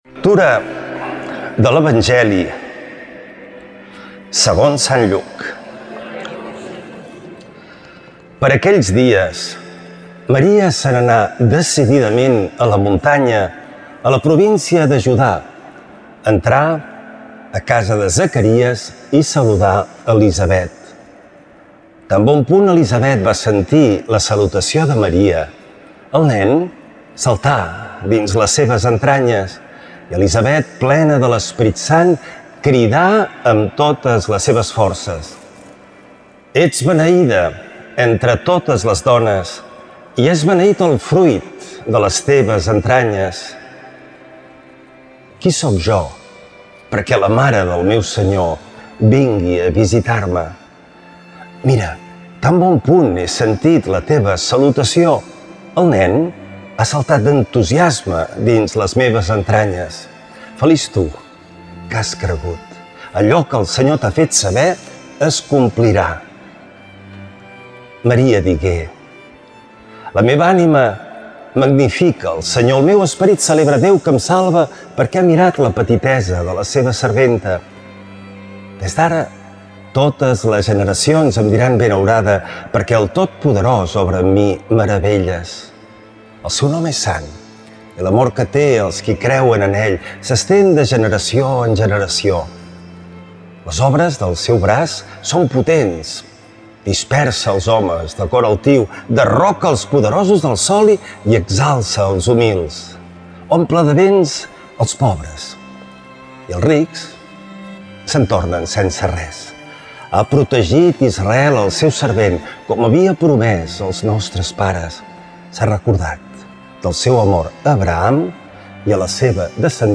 Lectura de l’evangeli segons sant Lluc